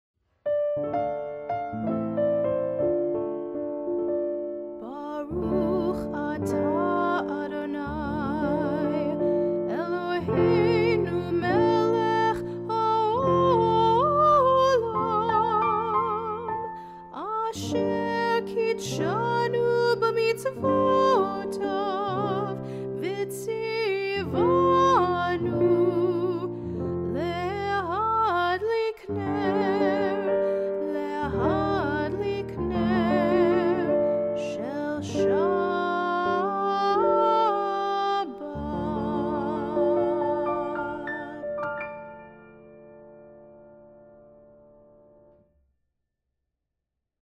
sing the candle blessing.